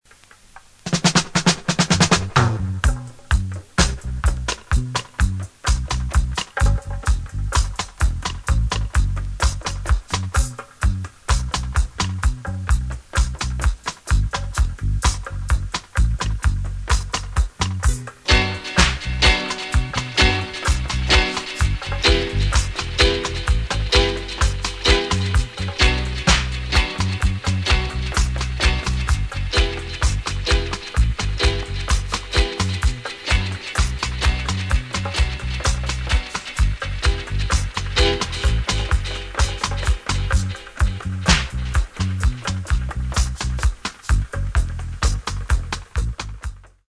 Recorded: Ariwa Studio